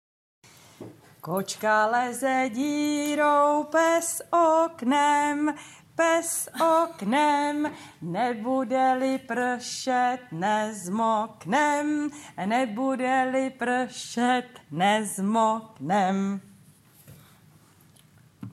Une petite comptine en langue tchèque simple à apprendre
Comptine-tcheque.mp3